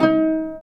Index of /90_sSampleCDs/Roland - String Master Series/STR_Vcs Marc-Piz/STR_Vcs Pz.3 dry